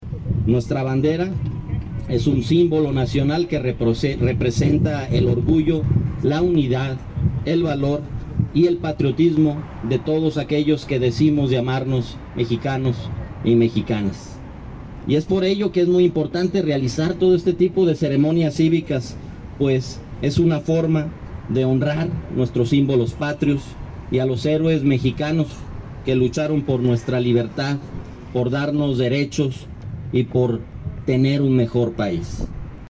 AudioBoletines
Rodolfo Gómez Cervantes, secretario del ayuntamiento